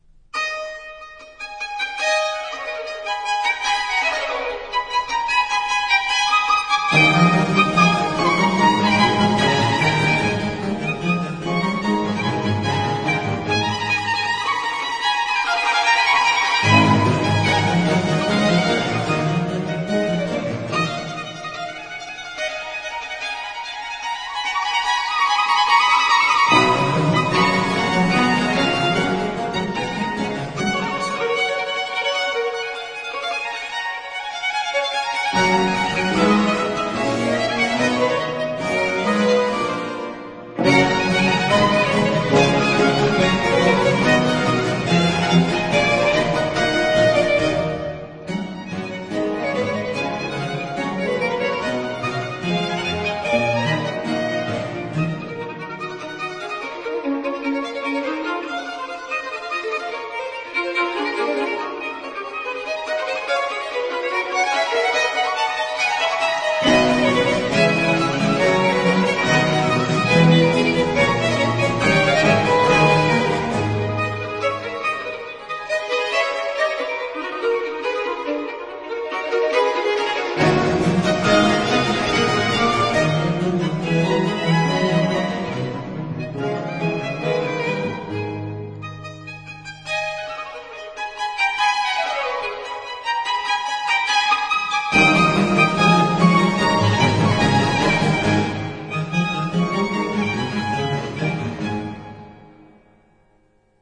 其聲響是豐富的。
試聽三則是協奏曲。
這些曲子好聽、美麗豐富。